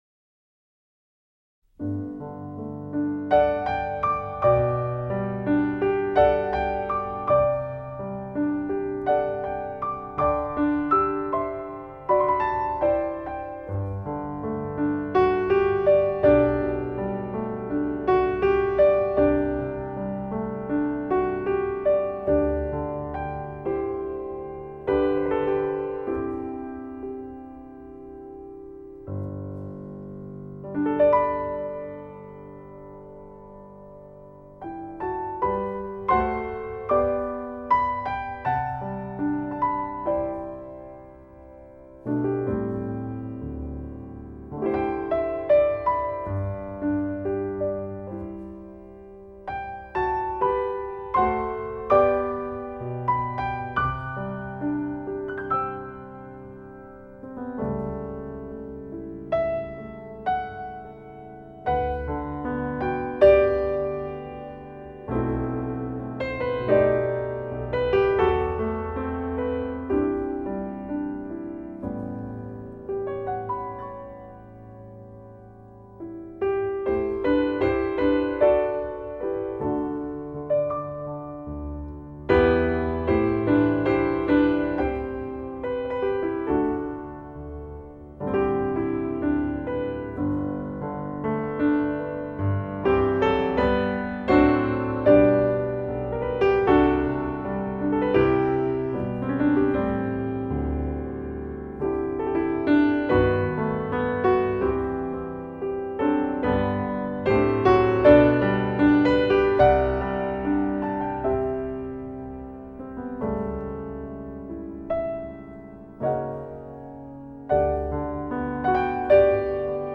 [light]獨特悠閒的音樂空間，36首最能掌握時代脈動的爵士樂精選 !